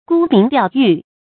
gū míng diào yù
沽名钓誉发音
成语正音 钓，不能读作“ɡōu”。